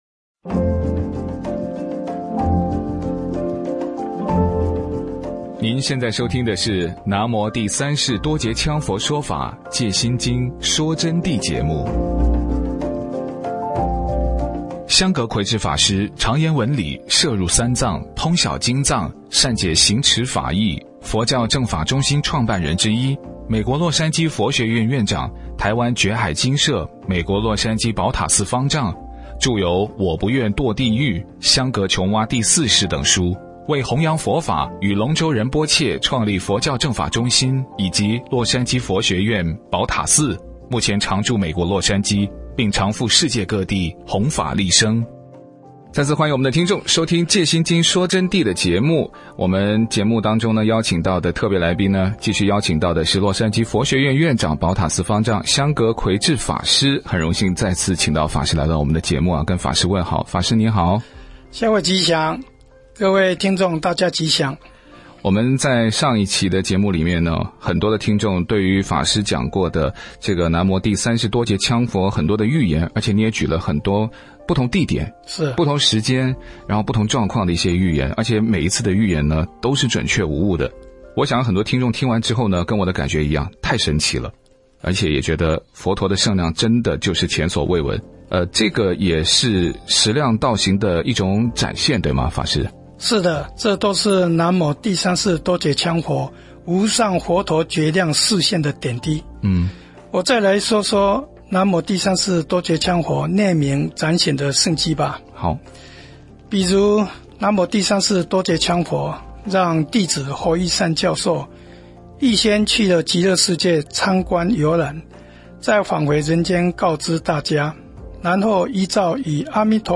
佛弟子访谈（二十四）南无羌佛的佛陀觉量示现体显佛法的本质及佛教界的现状